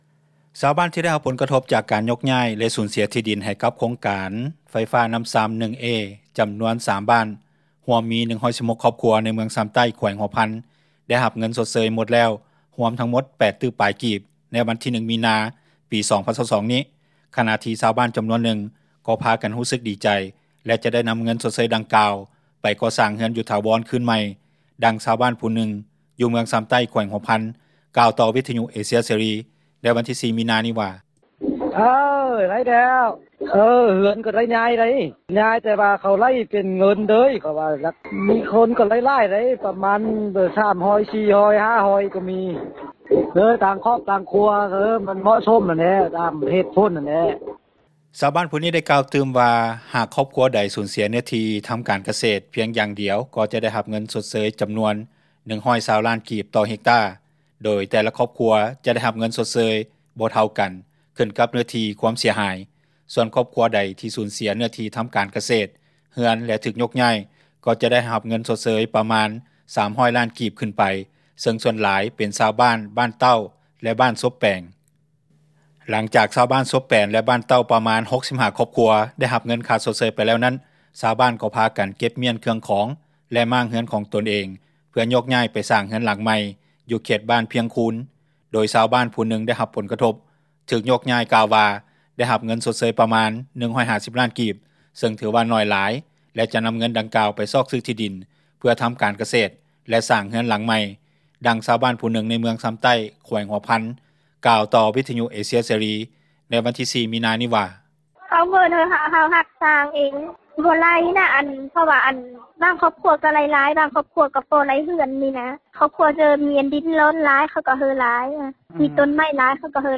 ດັ່ງຊາວບ້ານຜູ້ນຶ່ງຢູ່ເມືອງຊຳໃຕ້ ແຂວງຫົວພັນກ່າວຕໍ່ວິທຍຸເອເຊັຽເສຣີ ໃນມື້ວັນທີ 04 ມີນານີ້ວ່າ:
ດັ່ງເຈົ້າໜ້າທີ່ເມືອງຊໍາໃຕ້ ແຂວງຫົວພັນກ່າວຕໍ່ວິທຍຸເອເຊັຽເສຣີໃນມື້ວັນທີ 04 ມີນານີ້ວ່າ: